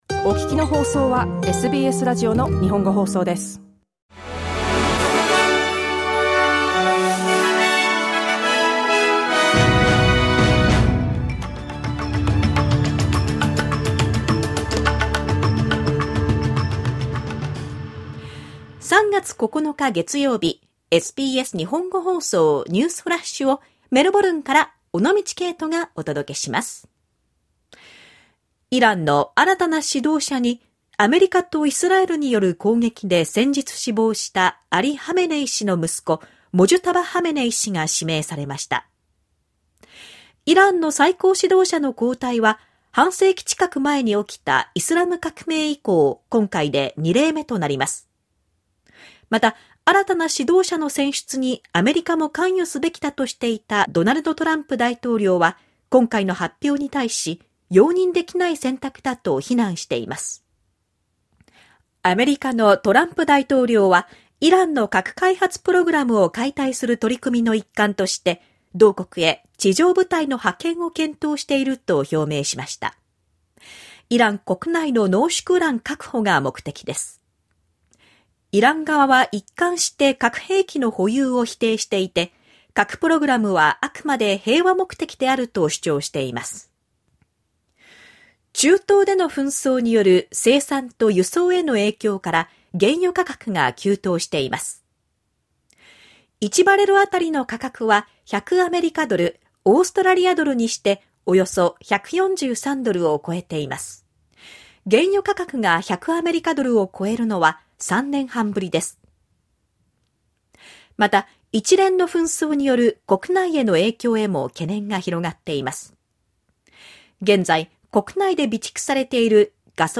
SBS Japanese Newsflash Monday 9 March